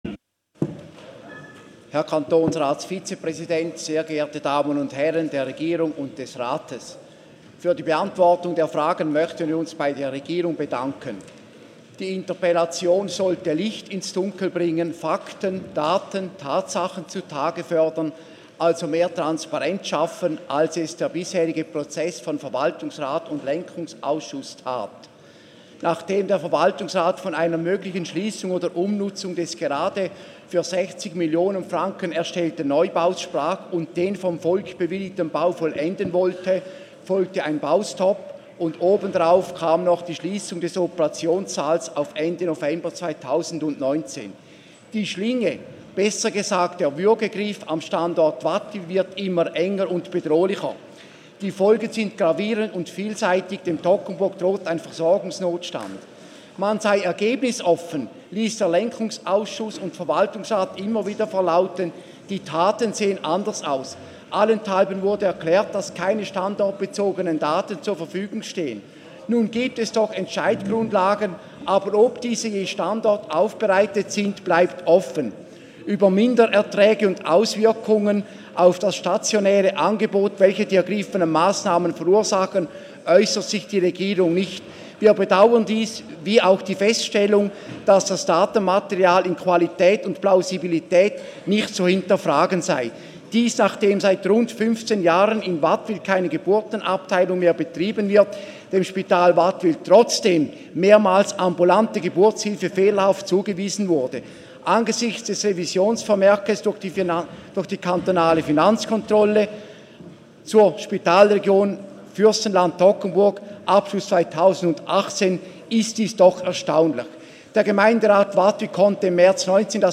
Session des Kantonsrates vom 16. bis 18. September 2019